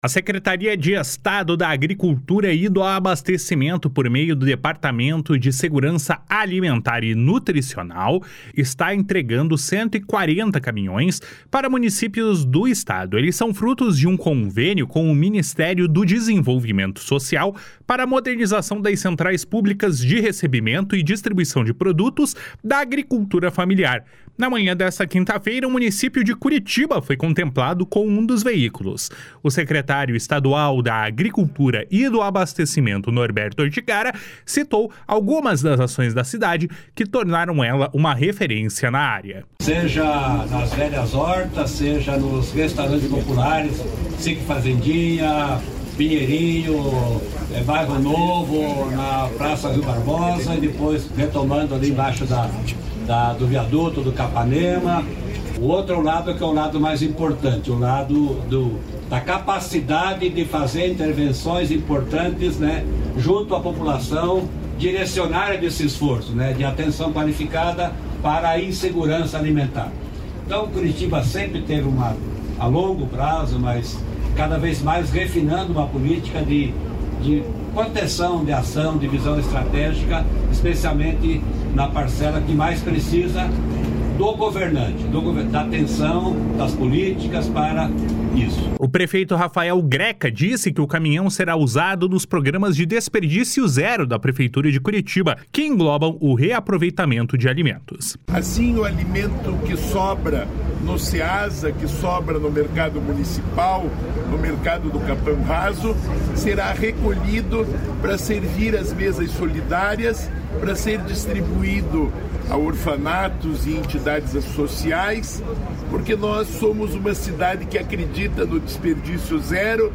// SONORA NORBERTO ORTIGARA // ORTIGARA 01
O prefeito Rafael Greca disse que o caminhão será usado nos programas de desperdício zero da Prefeitura de Curitiba, que englobam o reaproveitamento de alimentos. // SONORA RAFAEL GRECA // GRECA 01